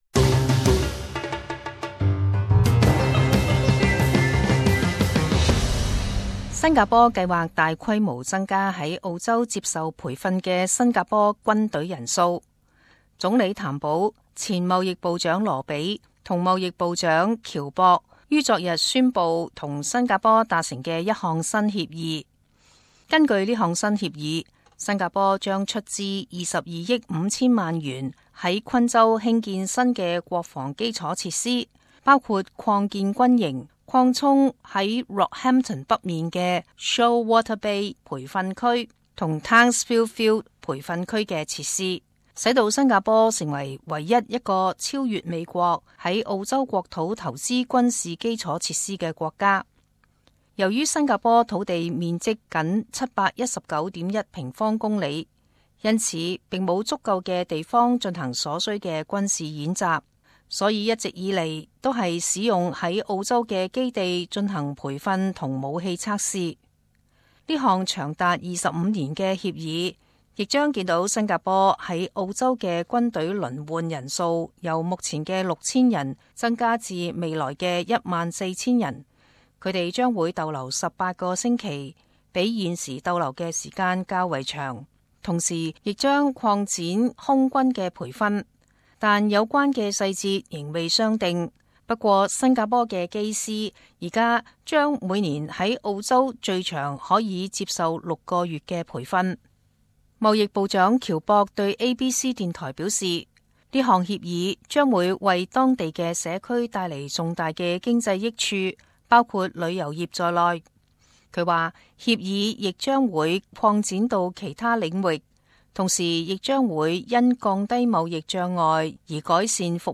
时事报导 - 澳洲与新加坡达成22.5亿元国防协议